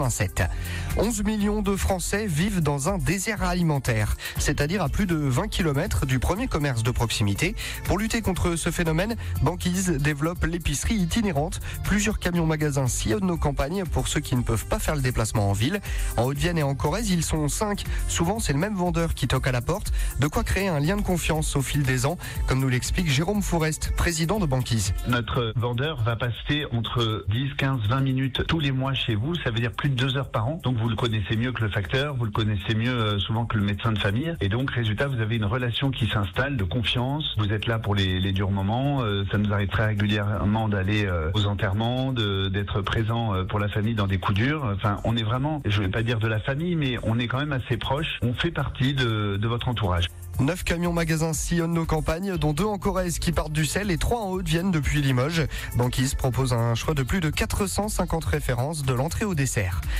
Notre entreprise a fait l’objet d’un sujet diffusé dans trois flashs info à 7h30, 8h00 et 8h30, mettant en lumière notre modèle d’épicerie rurale itinérante et notre rôle dans la lutte contre les déserts alimentaires.